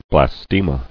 [blas·te·ma]